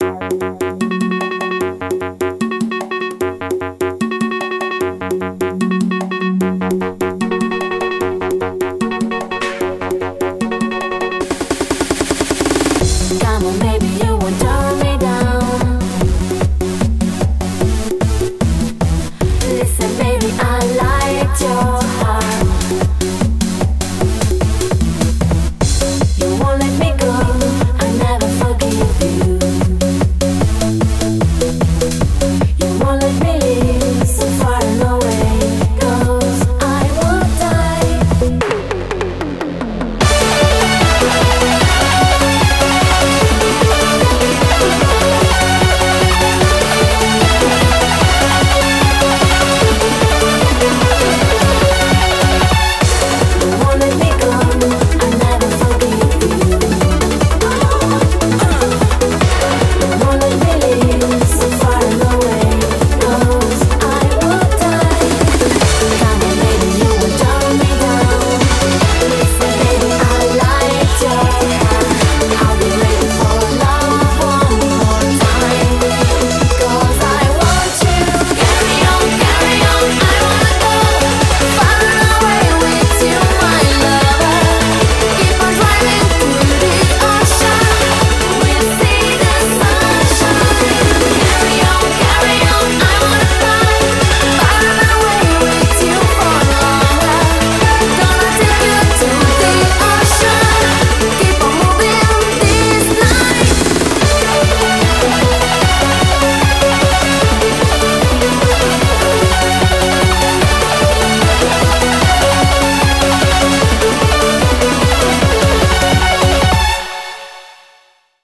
BPM75-150
Audio QualityPerfect (Low Quality)